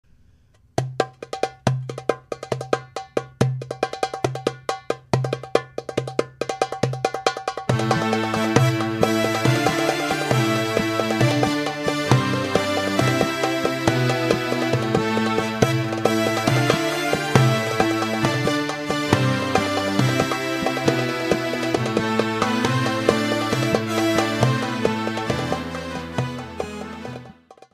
USKADAR - upbeat Turkish folk classic